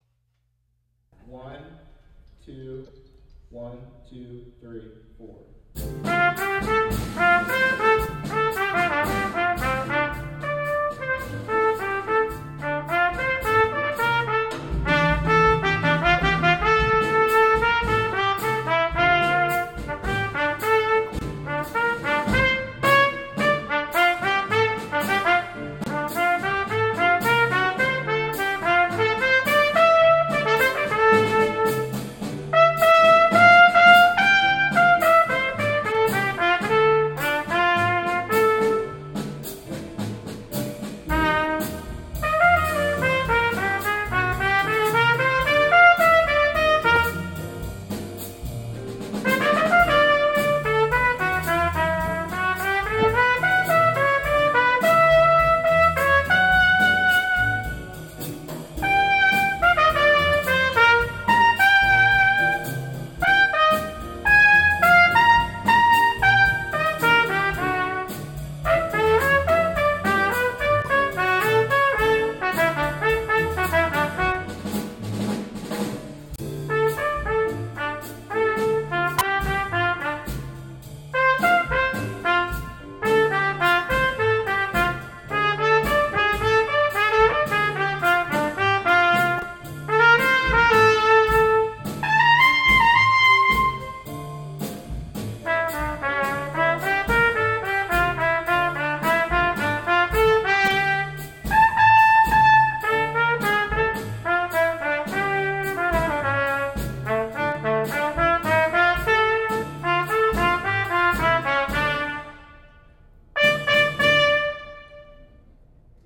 Trumpet
Bossa-Rock Etude
Performance with Accompaniment
bossa-rock-w_bkgrd-v2.m4a